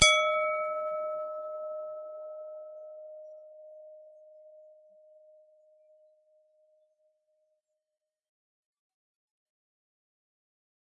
高调的玻璃碗 " 木头打高的玻璃碗 13
描述：反对高音调的玻璃碗的木轻拍。用Zoom Q3HD录制。使用Reaper的ReaFir插件降低噪音。
标签： 命中 木材 玻璃 抽头
声道立体声